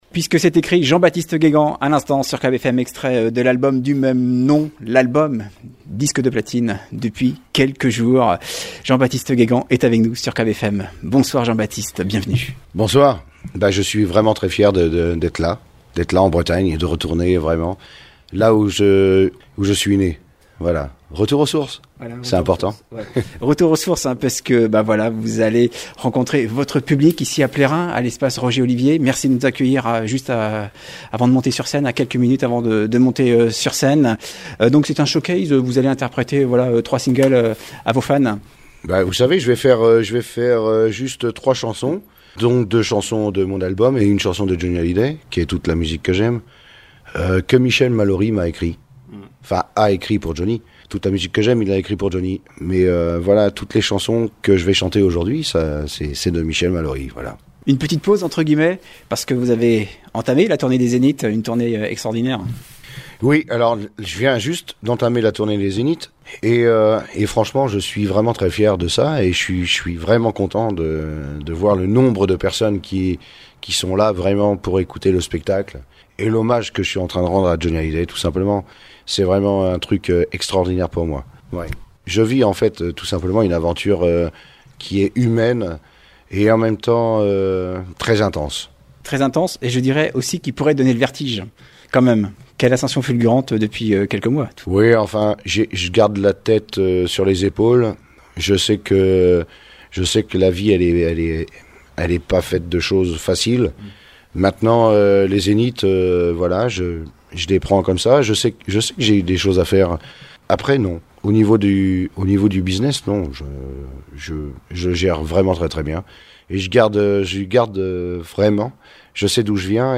Entretien avec Jean-Baptiste Guégan